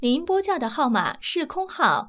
ivr-call_cannot_be_completed_as_dialed.wav